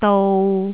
韻母 iu 到頁頂
它們的粵拼韻母都是〔iu〕。